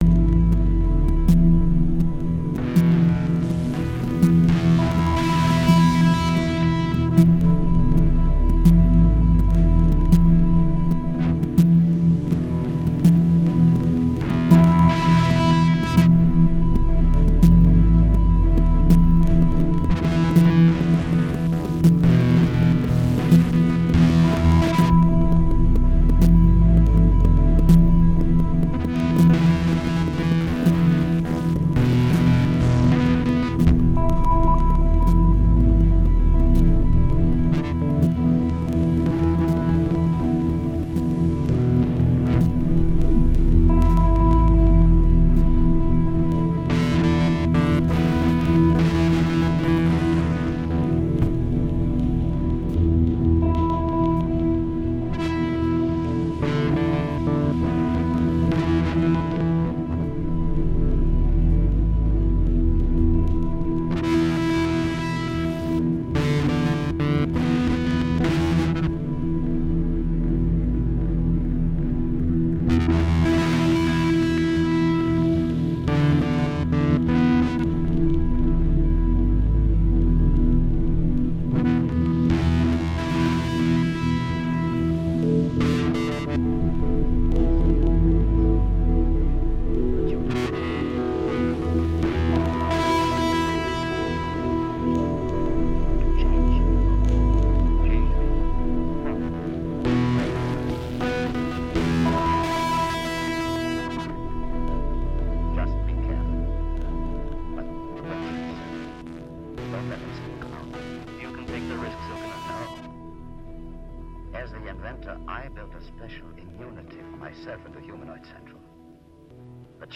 08 May 2003 - Extrapool, Nijmegen NL
Mp3 excerpts from the concerts